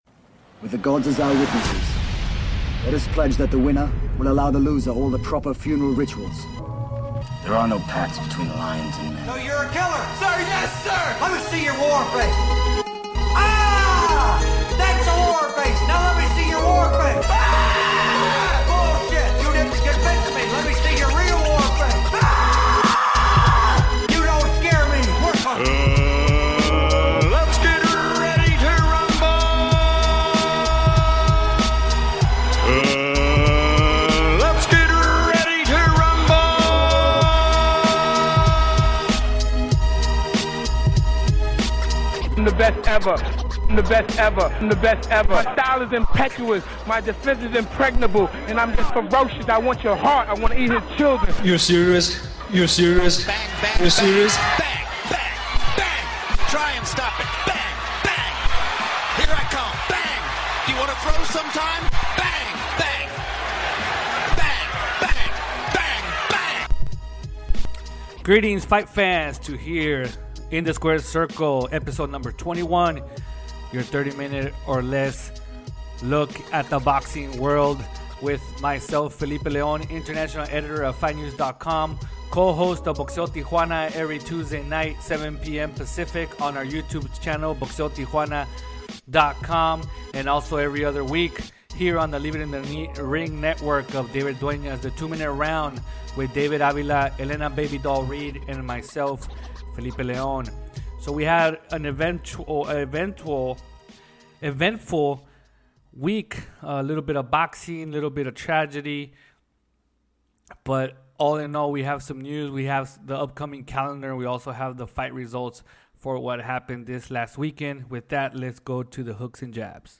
passes the latest news in the sport with a fast pace style of 30 minutes or less